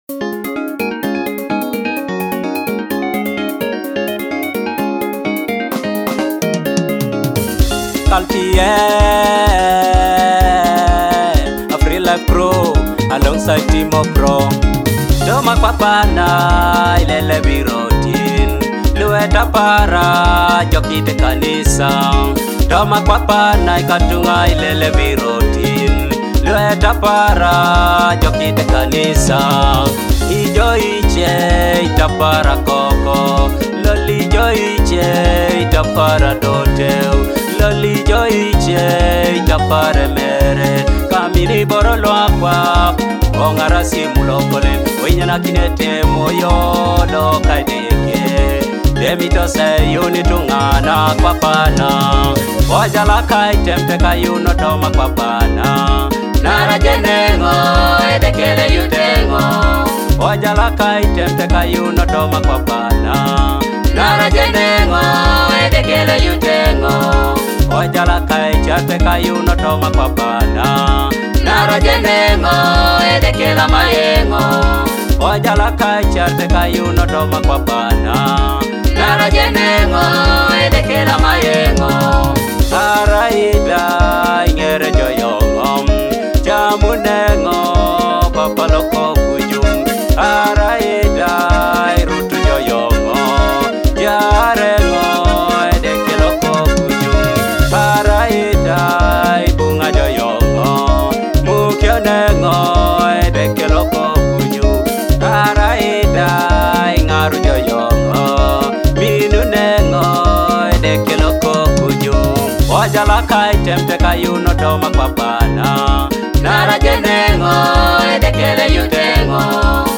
soul-stirring melodies
gospel masterpiece